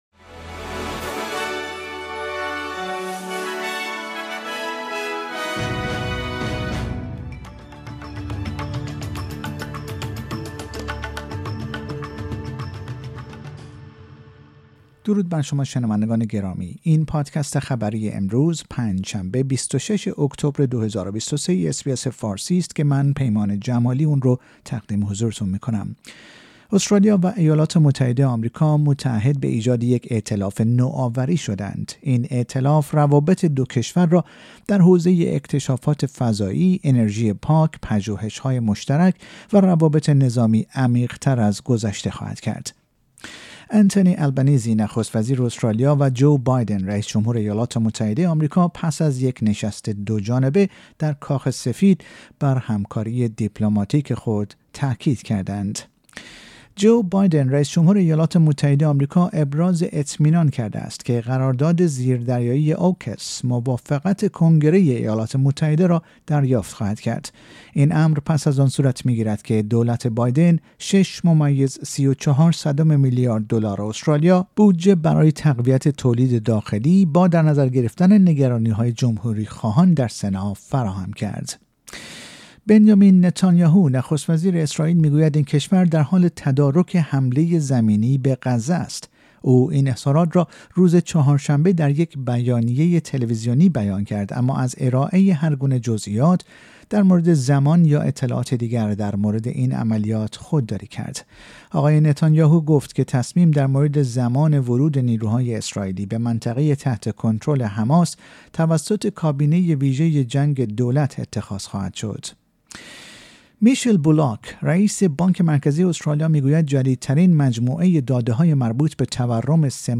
در این پادکست خبری مهمترین اخبار استرالیا و جهان در روز پنج شنبه ۲۶ اکتبر، ۲۰۲۳ ارائه شده است.